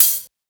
Closed Hats
edm-hihat-09.wav